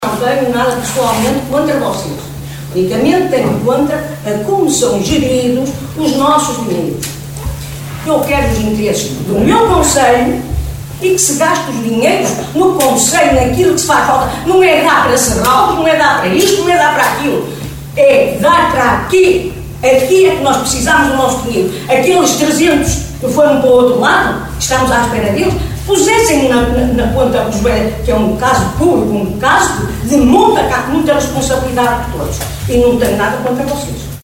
Desta vez o problema não foi abordado por nenhum eleito como tem acontecido até aqui, com inúmeras chamadas de atenção em reuniões do executivo, Assembleias Municipais e de Freguesia, mas sim pela voz de uma munícipe que no período dedicado à intervenção do público chamou a atenção para o perigo que constitui aquela travessia.